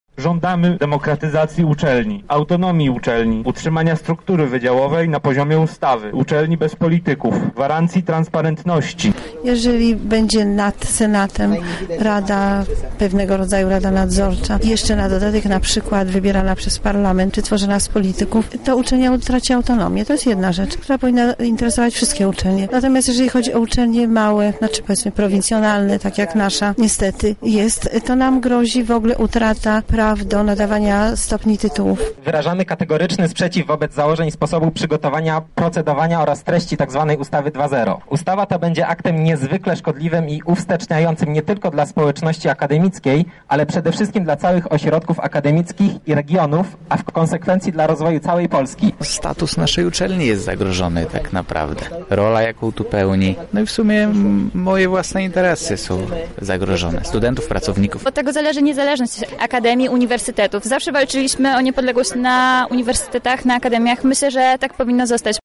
Z protestującymi studentami rozmawiał nasz reporter.